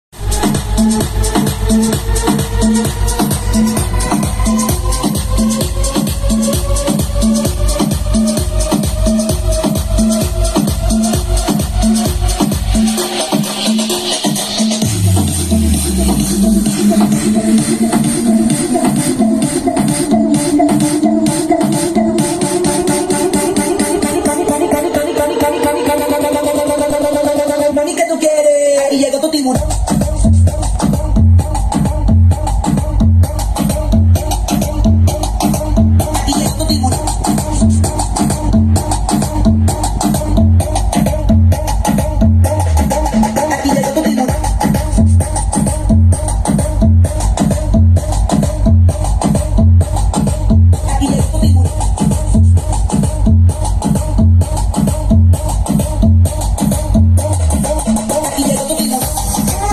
minicomponente LG CM7420 mini hi-fi system METAL BASS audio espectacular yeaahhh